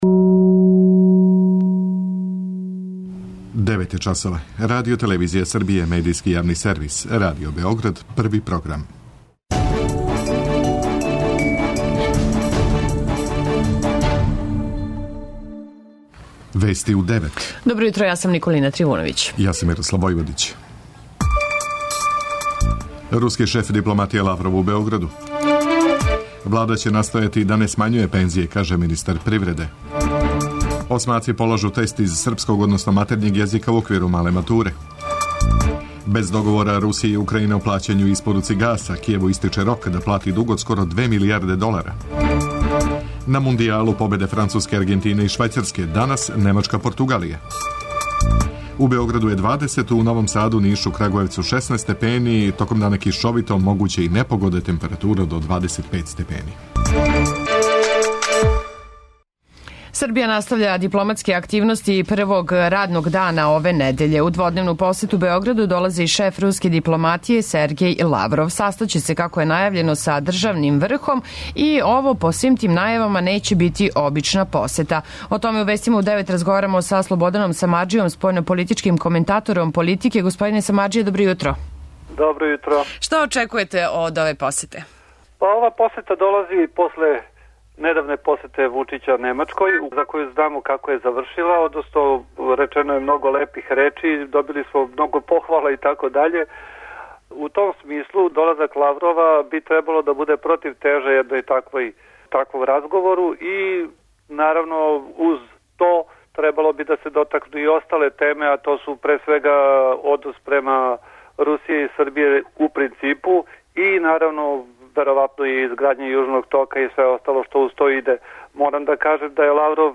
преузми : 11.17 MB Вести у 9 Autor: разни аутори Преглед најважнијиx информација из земље из света.